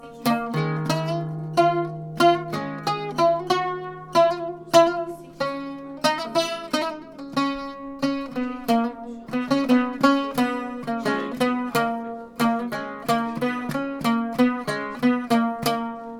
Famille : cordes pincées
On en joue avec les doigts ou avec un plectre (instrument qui sert à pincer les cordes).
Oud